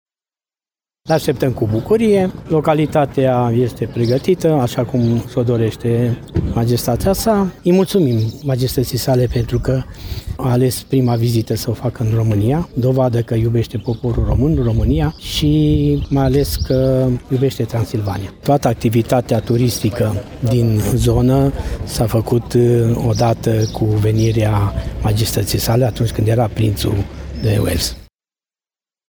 În întâmpinarea regelui a ieșit și primarul comunei Bunești, Mircea Pălășan:
MIRCEA-PALASAN-REGE.mp3